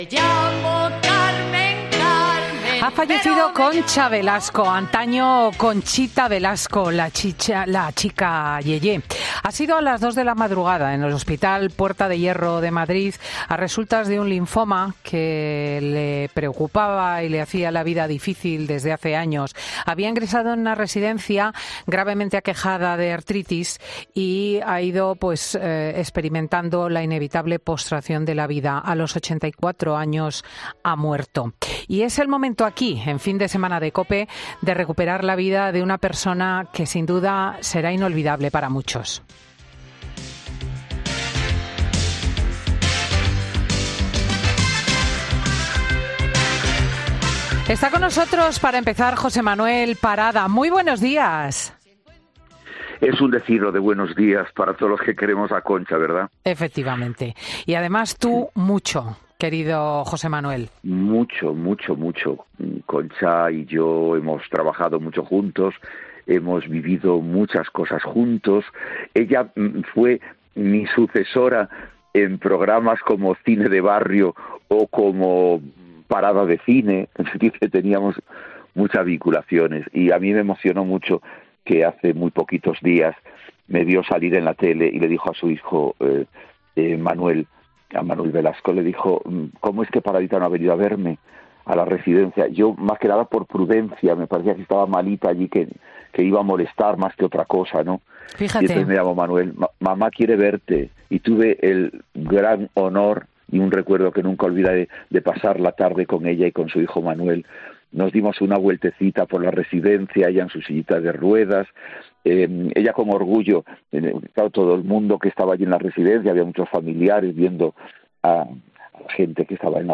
El presentador recuerda en Fin de Semana COPE, a la artista vallisoletana, que ha fallecido hoy.
José Manuel Parada recuerda a Concha Velasco en Fin de Semana